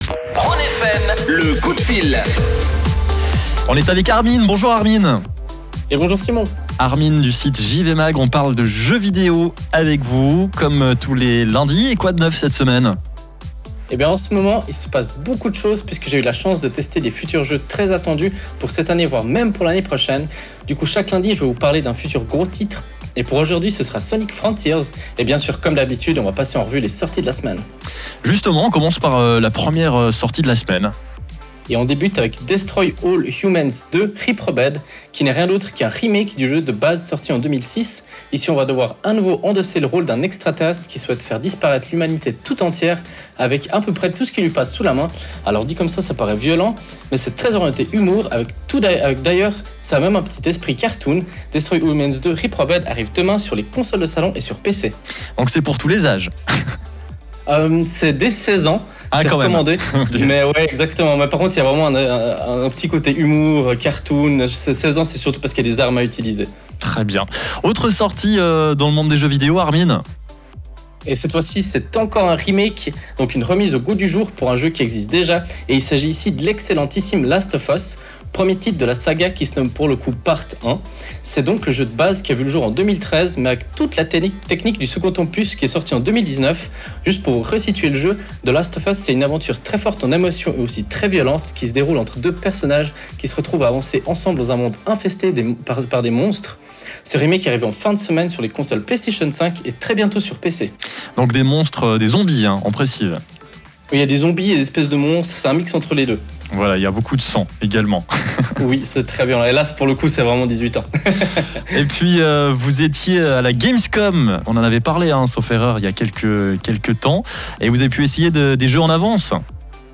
Comme tous les lundis, à 17h20, nous avons la chance de vous proposer une petite chronique orientée actualité gaming, via la radio Rhône FM.
Le direct est à réécouter juste en dessus.